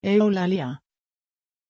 Aussprache von Eulalia
pronunciation-eulalia-sv.mp3